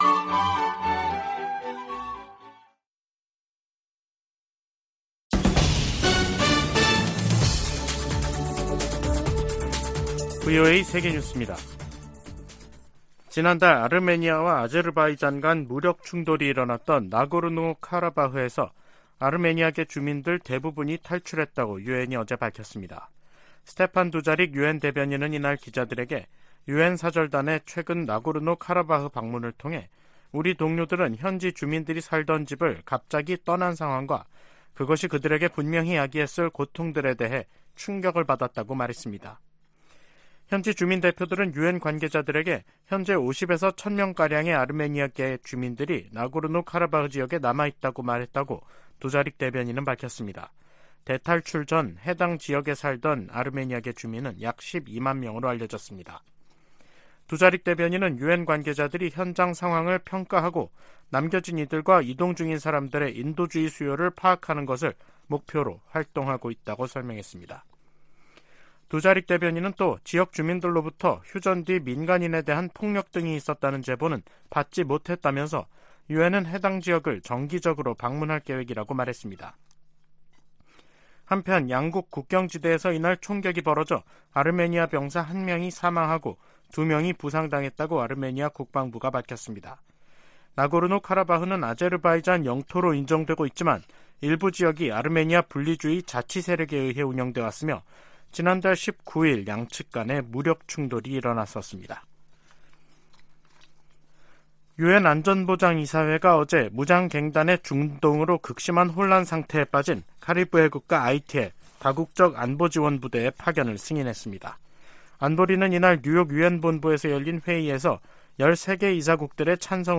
VOA 한국어 간판 뉴스 프로그램 '뉴스 투데이', 2023년 10월 3일 2부 방송입니다. 미 국무부는 중국이 대북 영향력을 활용해 북한을 외교로 복귀시켜야 한다고 밝혔습니다. 유엔총회 제1위원회 회의에서 미국은 북한과 러시아 간 무기 거래가 국제 평화에 대한 중대한 위협이라고 지적했습니다. 북한이 군사정찰위성을 세 번째로 쏘겠다고 공언한 10월에 접어들면서 관련국들이 북러 군사 협력 가시화에 촉각을 곤두세우고 있습니다.